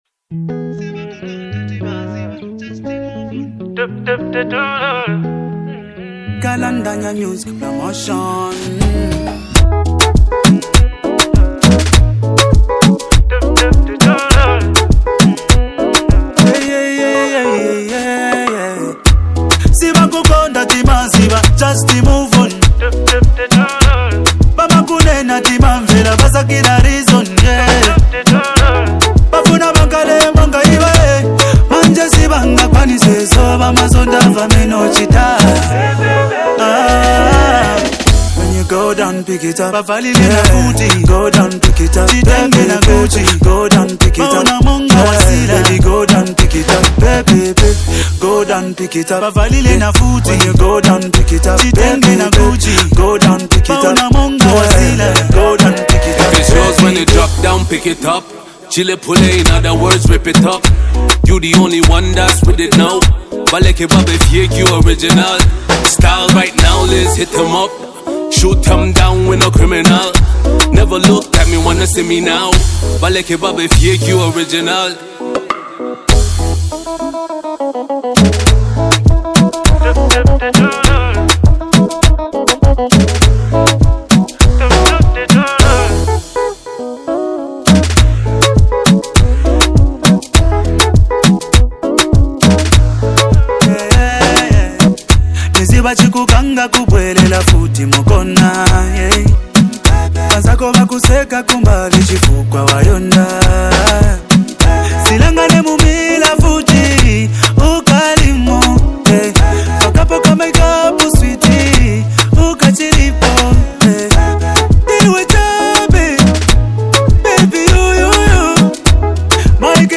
is known for his Afrobeat and Zambian pop music.
creating a lively and engaging song.
upbeat rhythms and catchy melodies